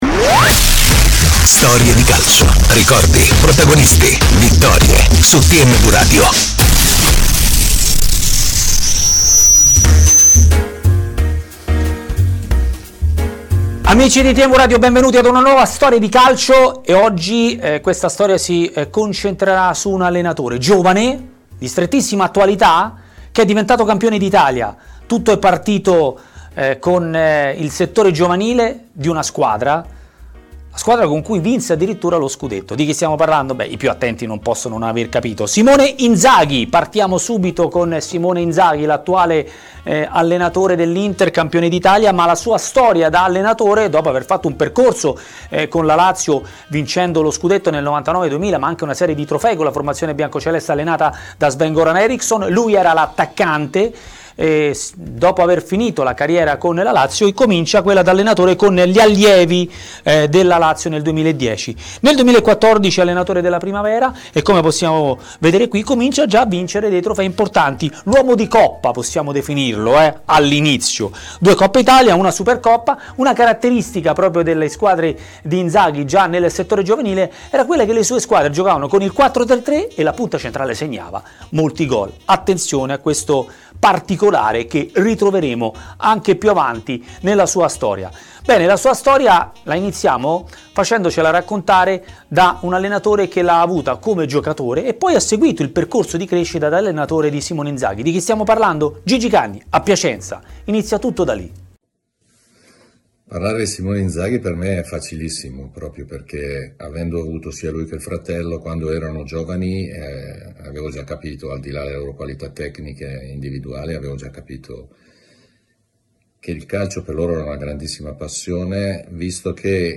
A Storie di Calcio, trasmissione di TMW Radio, a parlarne diversi opinionisti.